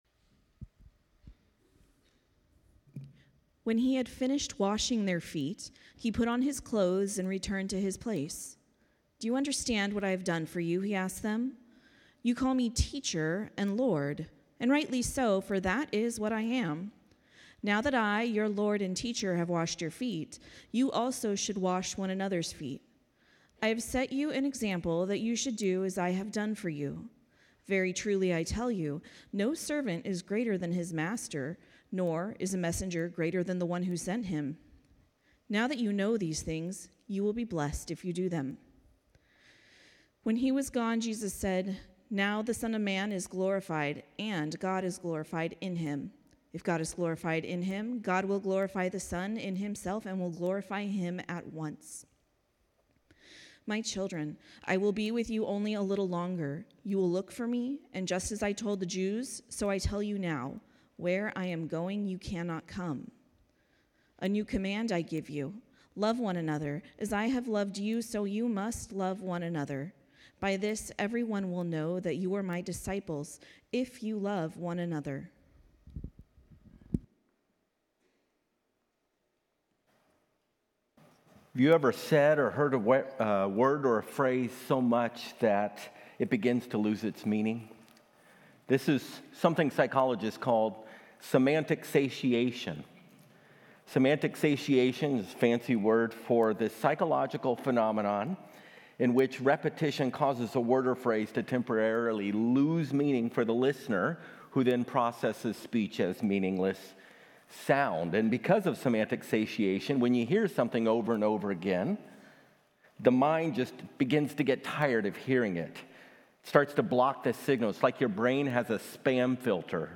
Join us each week as we dive deep into the Word of God and explore the life-transforming message of Jesus and the grace He offers us. In each episode, we bring you the dynamic and inspiring Sunday messages delivered by our passionate and knowledgeable pastors.